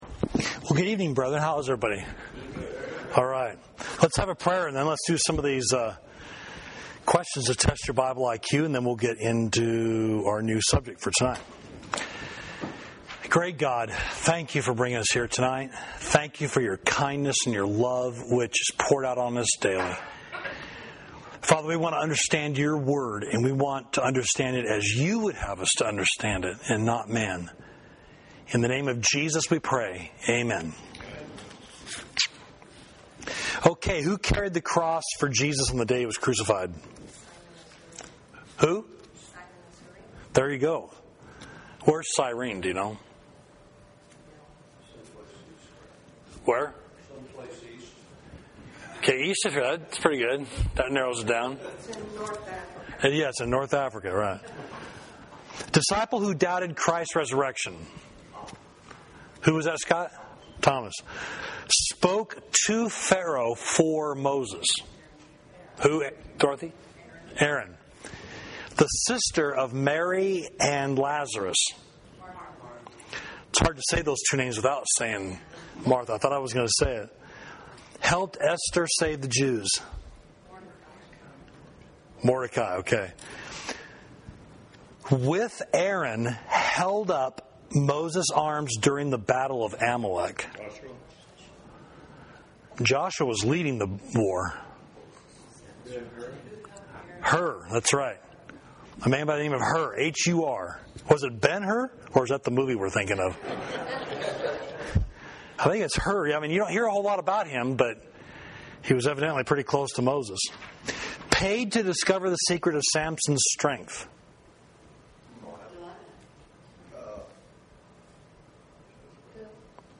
Class: When the Seemingly Innocent Suffer